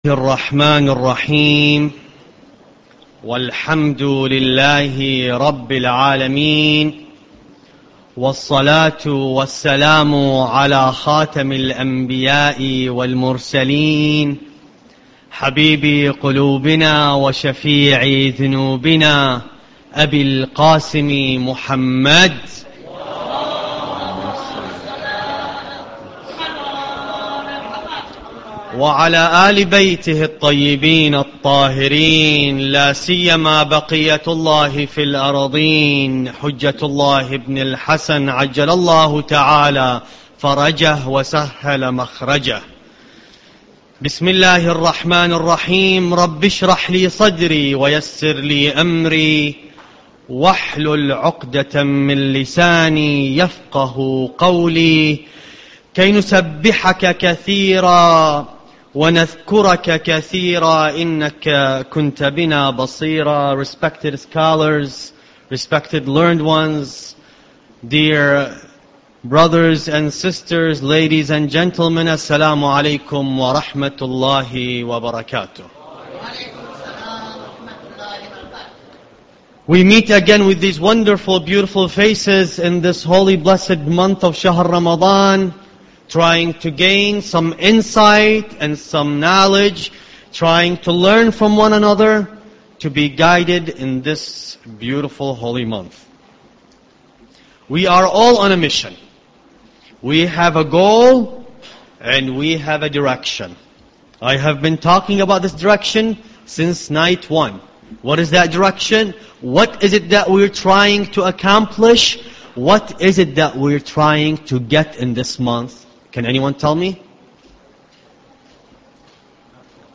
Lecture -8